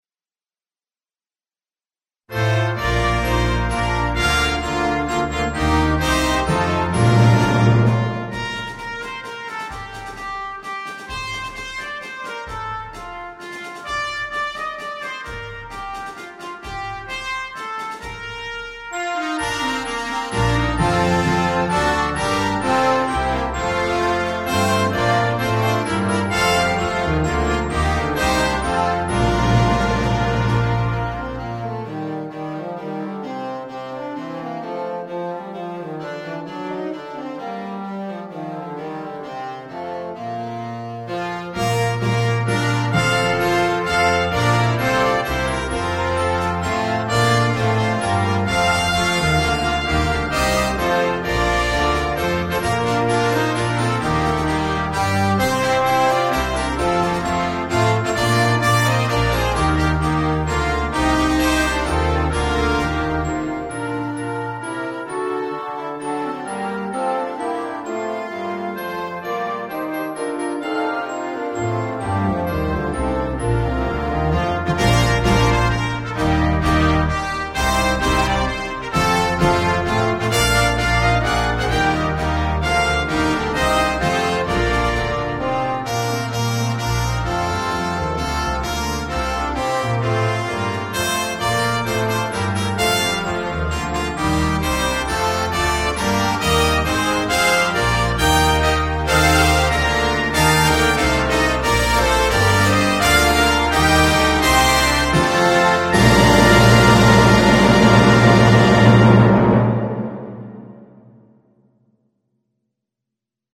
A full concert band version
popular secular sixteenth-century English carol